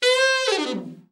ALT FALL   7.wav